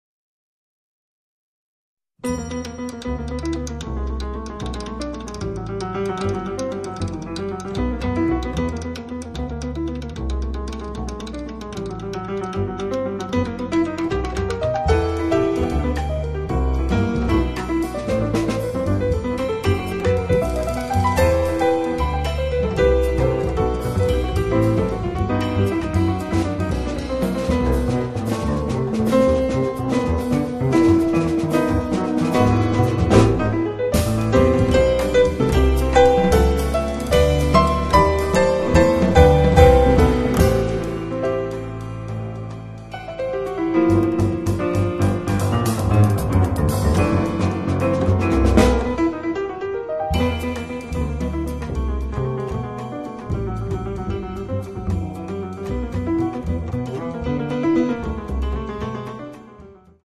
pianoforte, sintetizzatore, samplers, live electronics
contrabbasso, basso elettrico
batteria, percussioni